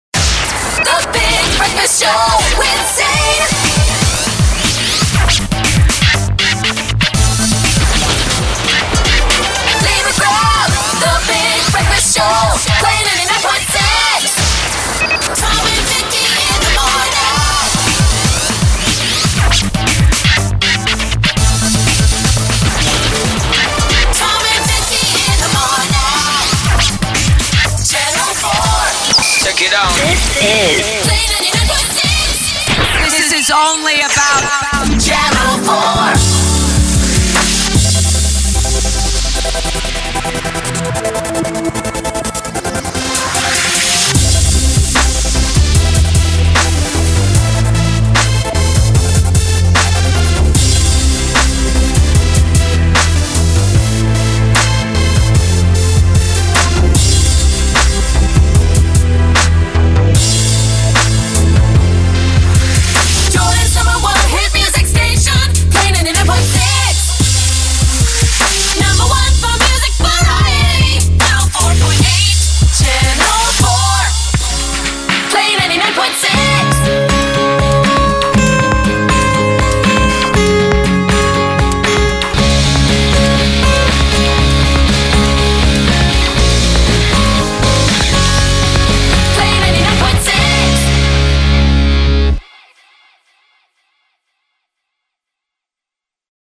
The 2-punch works great as a shotgun between songs too.
And here are the cuts in montage form.